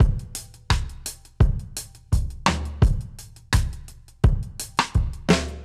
Db_DrumsA_Dry_85-03.wav